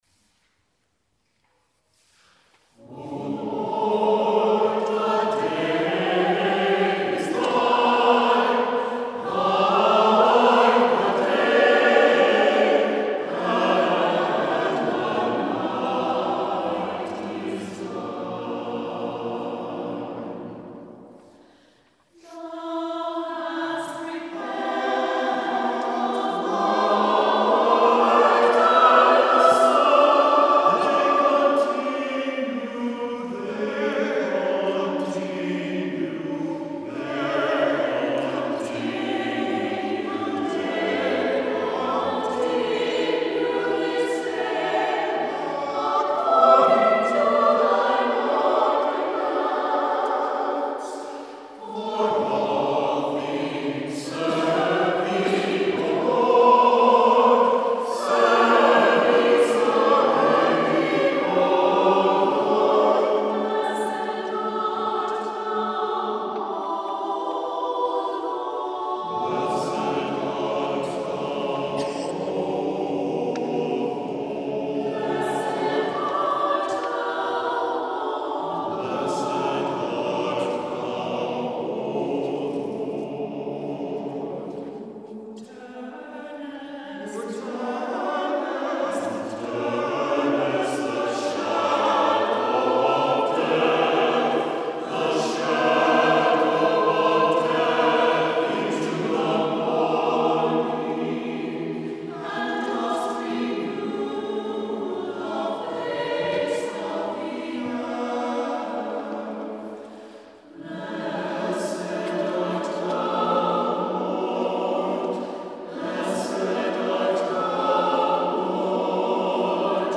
• Music Type: Choral
• Voicing: SATB
• Accompaniment: a cappella
antiphonal writing, homophony, imitation and modality.